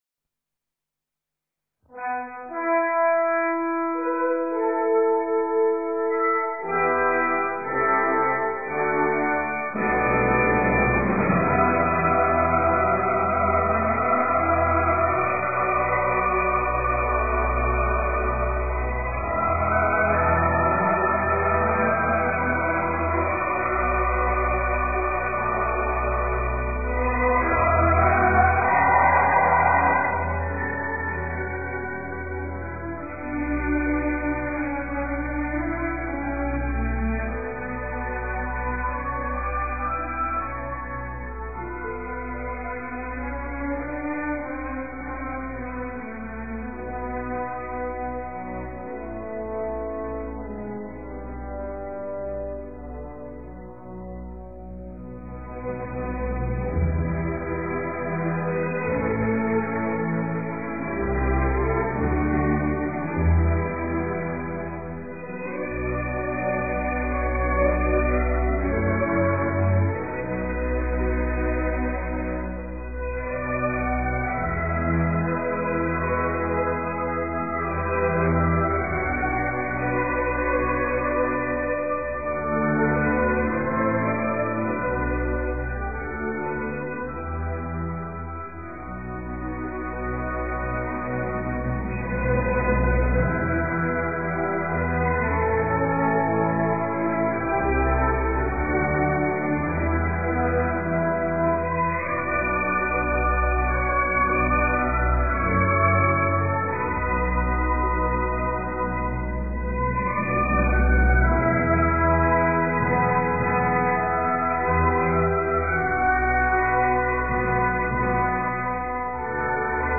ORQUESTAS